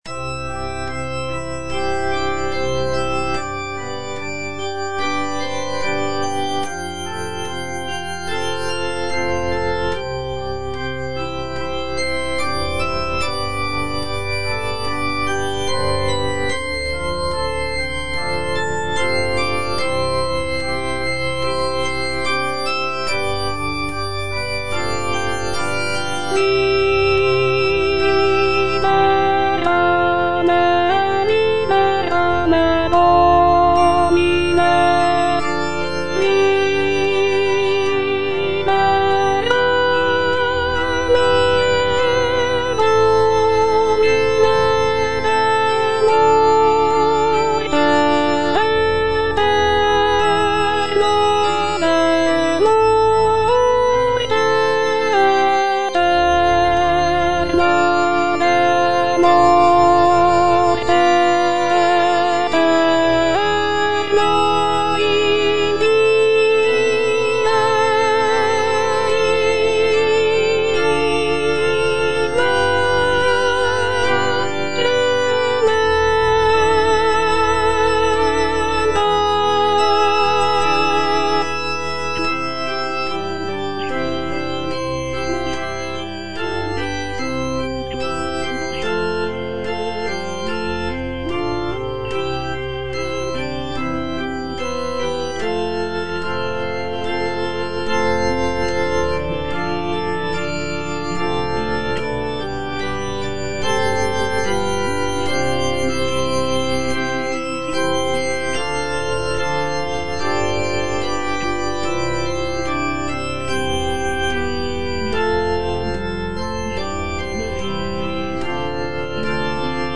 Alto (Voice with metronome) Ads stop
is a sacred choral work rooted in his Christian faith.